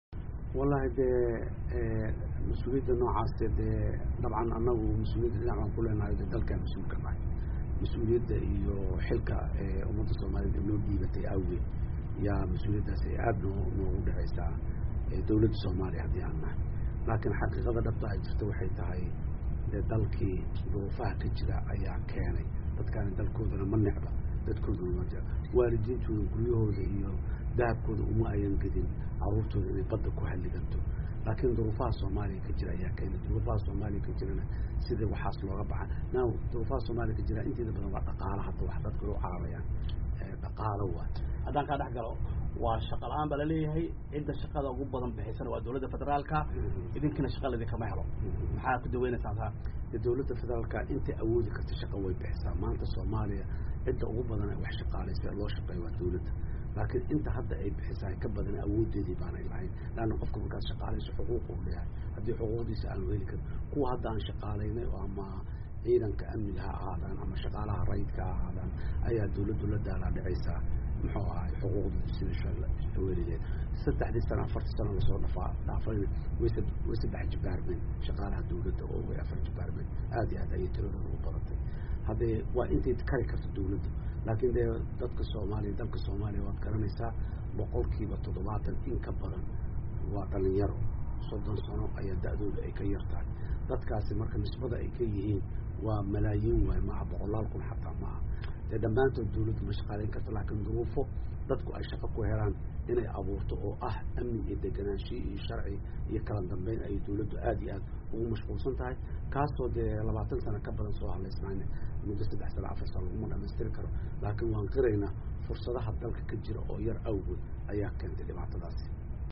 Wareysi uu siiyey VOA, ayuu madaxweynaha ku sheegay in tahriibka ay keeneen duruufo dalka ka Soomaaliya ka jiro, dowladda Soomaaliyana ay qeyb ku leedahay mas’uuliyadda, maadaama ayada ay dalka mas’uul ka tahay.